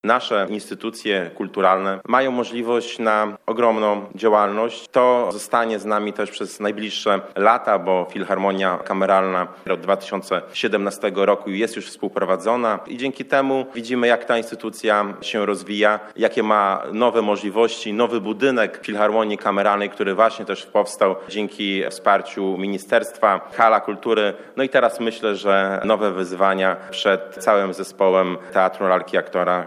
Prezydent Miasta, Mariusz Chrzanowski dodał, że dzięki temu instytucja będzie mogła jeszcze bardziej się rozwijać.